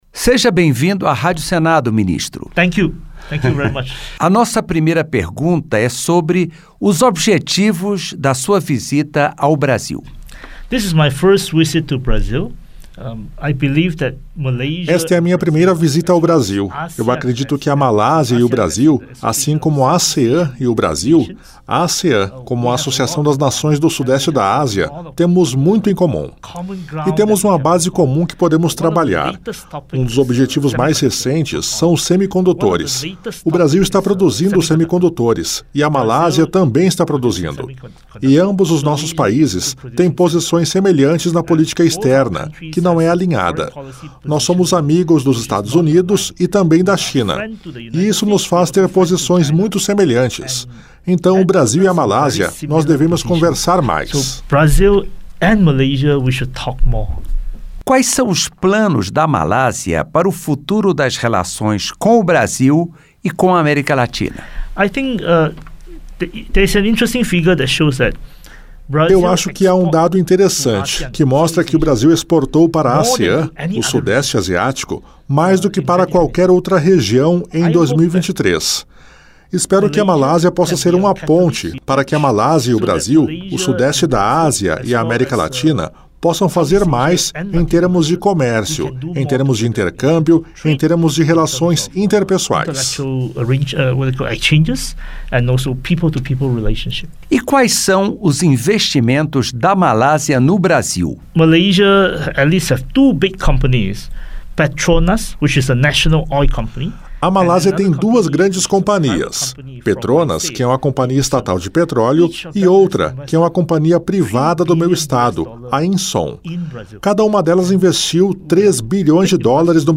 A Malásia quer ser uma ponte entre o Brasil e o Sudeste Asiático. É o que afirma o vice-ministro de Investimento, Comércio e Indústria da Malásia, Liew Chin Tong, que visitou o Brasil e foi recebido na Rádio Senado.